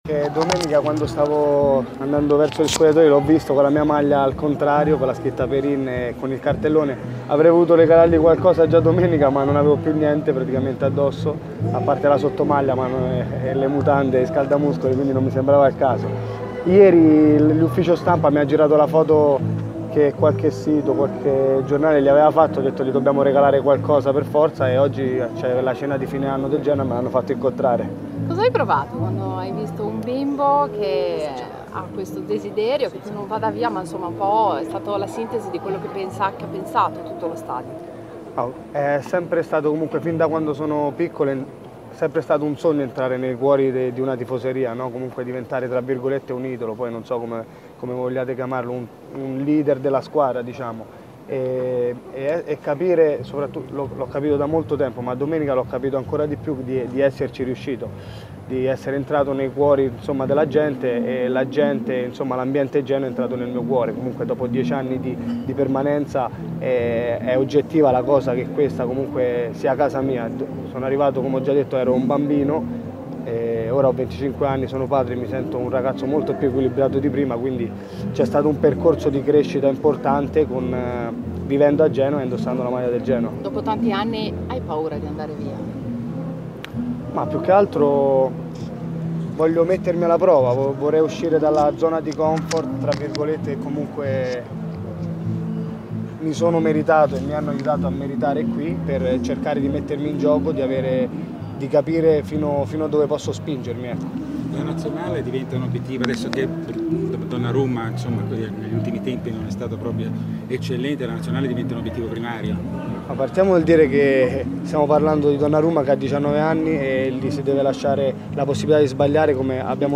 Ospite del Gala "Insieme per il Gaslini" il portiere del Genoa Mattia Perin ha parlato ai microfoni della stampa presente.
Mattia Perin, portiere del Genoa, al Gala "Insieme per il Gaslini".